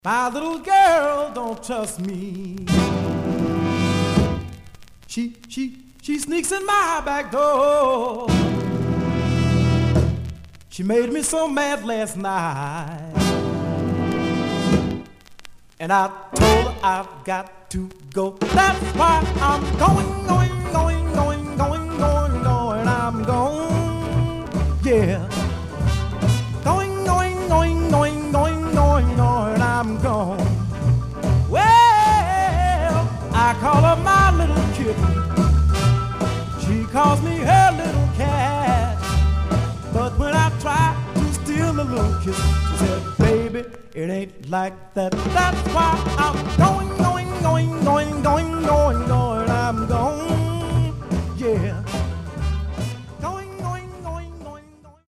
Some surface noise/wear
Mono
Rythm and Blues